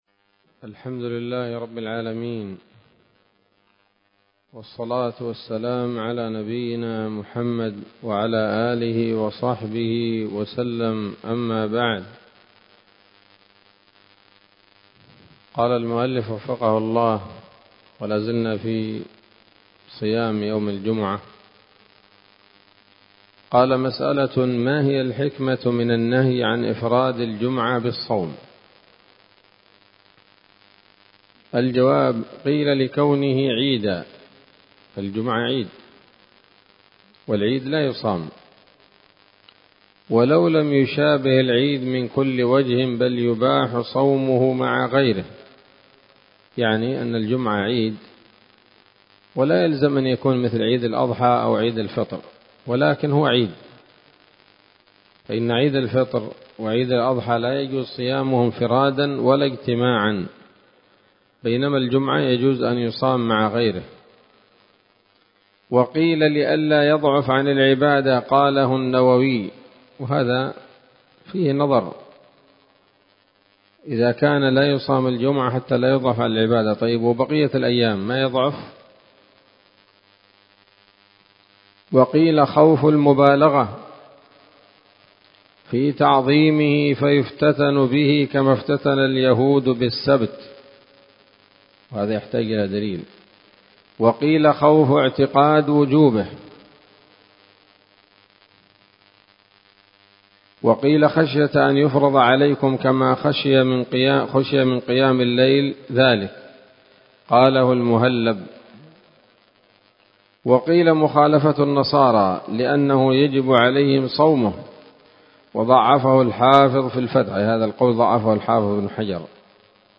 الدرس الخامس والعشرون من كتاب الصيام من نثر الأزهار في ترتيب وتهذيب واختصار نيل الأوطار